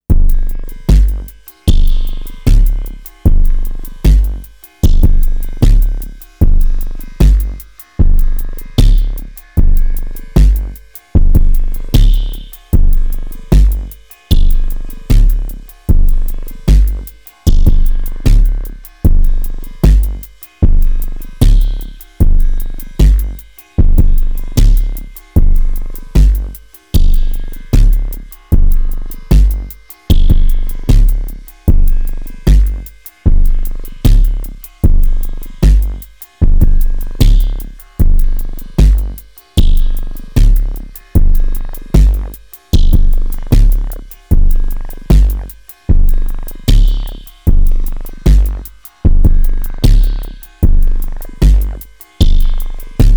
OT + Polivoks + Sherman